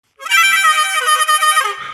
节奏型口琴
描述：hythmic blues/country 口琴 120 bpm D Harp for A
标签： 120 bpm Blues Loops Harmonica Loops 689.41 KB wav Key : A
声道单声道